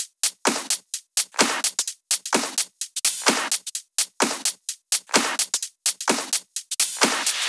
VDE 128BPM Renegade Drums 3.wav